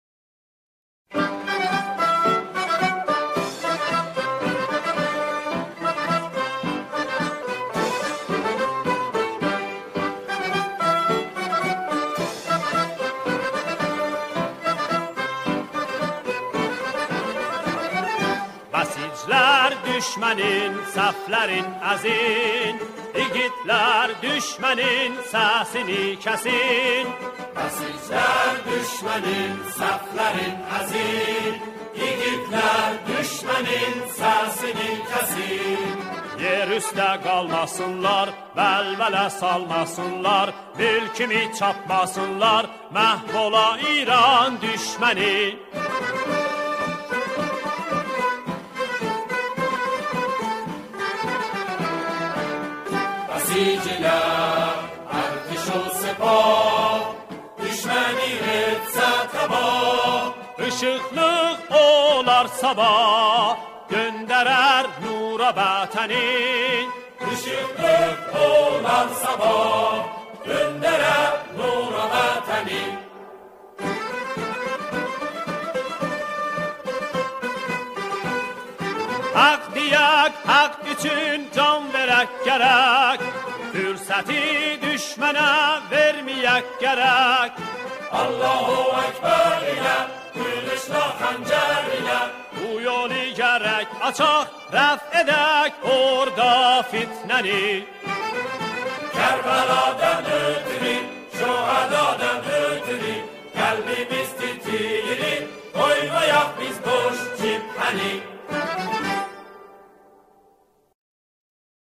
به گویش لری